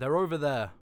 Combat Dialogue